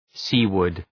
Shkrimi fonetik {‘si:wərd}
seaward.mp3